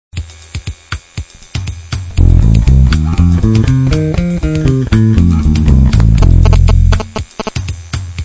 Cliquer sur Ex et vous  aurez un exemple sonore en F (Fa).